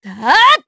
Xerah-Vox_Attack1_Madness_jp_b.wav